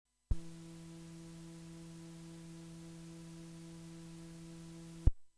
Привет, подскажите что можно сделать в следующем случае: проходит звук при закрытом фильтре и шум дикий.
UPD при ручках громкости осцилляторов в ноль тот же результат.